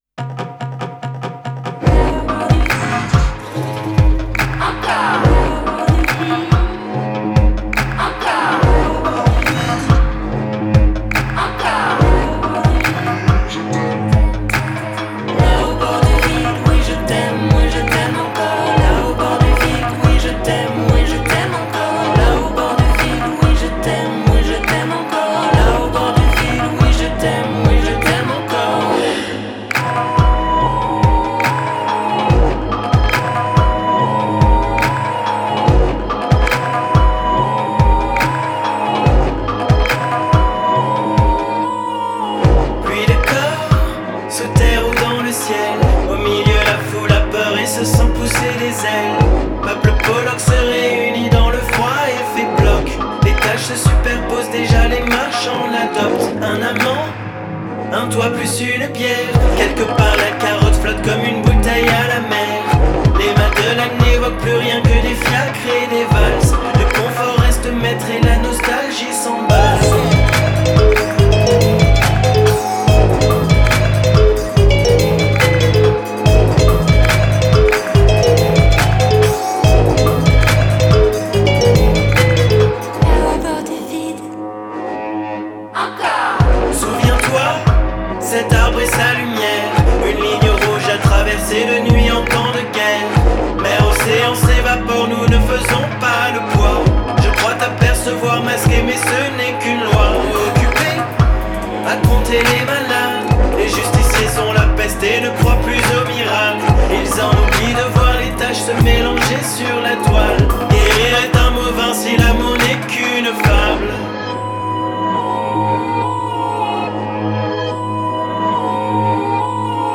a spectral and subdivided pop song
In-out music, moonwalked or faked.